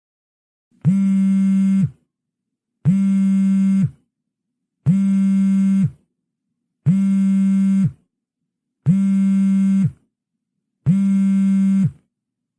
sfx_phone.mp3